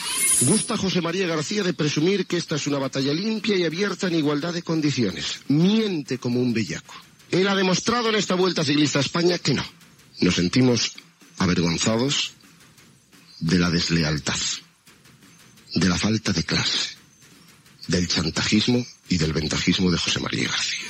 Transmissió de la "Vuelta ciclista a España 1993". Javier Ares critica l'actitud del periodista José María García que havia deixat Antena 3 per treballar a la COPE.
Esportiu
FM